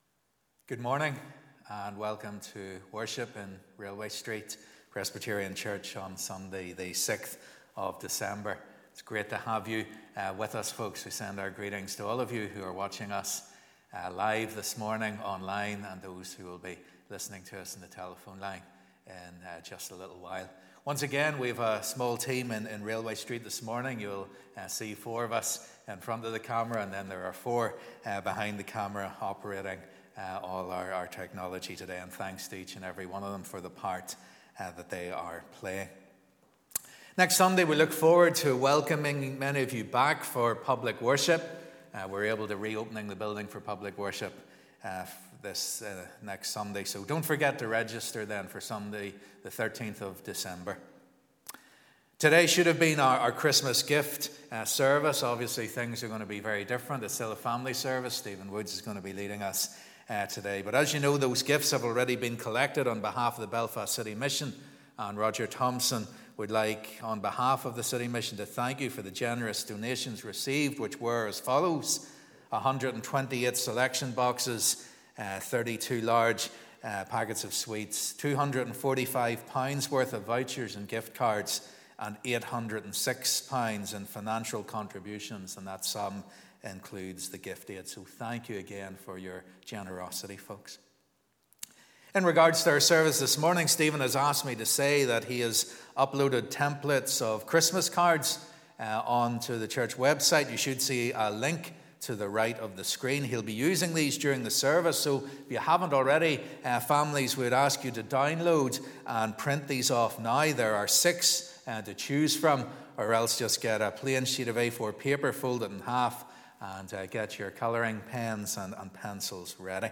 Christmas Gift Family Service